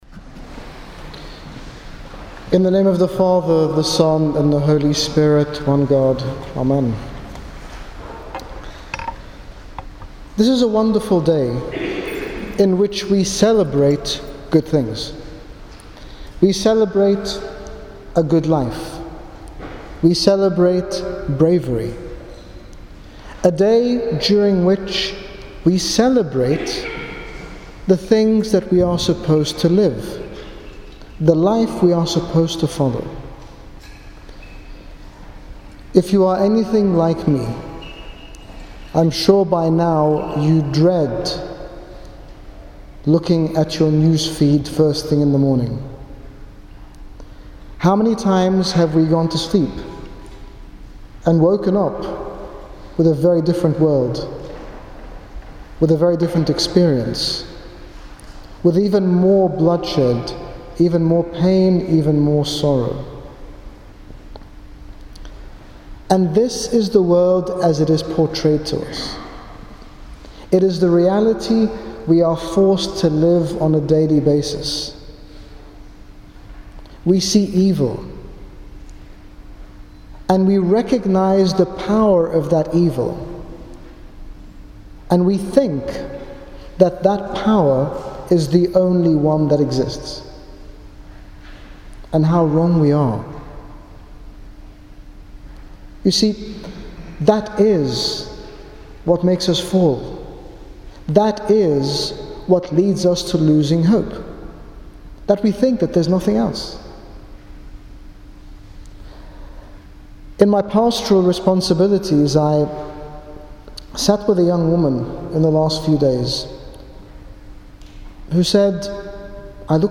Sermon by His Grace Bishop Angaelos, General Bishop of the Coptic Orthodox Church in the United Kingdom, during a special service in the Cathedral of Saint George in The Coptic Centre, Stevenage to mark Saint George’s Day on 23 April 2015.
Shephal Manor Celebration sermon st george day.MP3